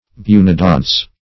Search Result for " bunodonts" : The Collaborative International Dictionary of English v.0.48: Bunodonta \Bu`no*don"ta\, Bunodonts \Bu"no*donts\, n. pl.